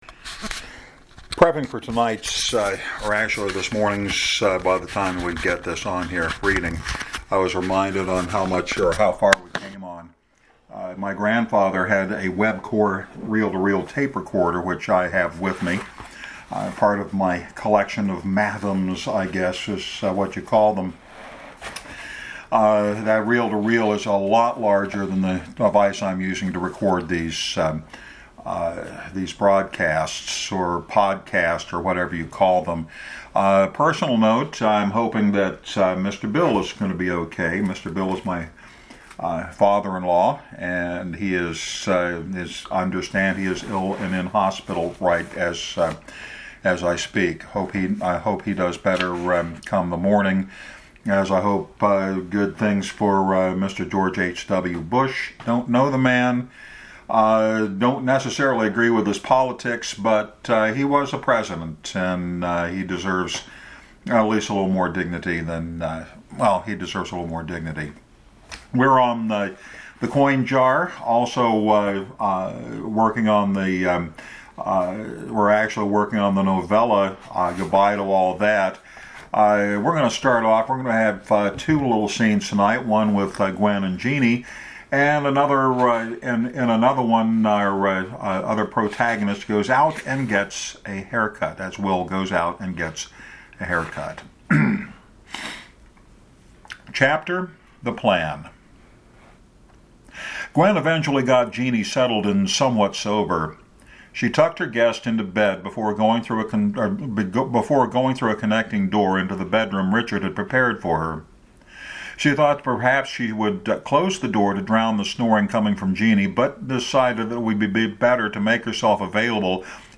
Getting this week’s reading out just a tad early so that the day job can be attended to.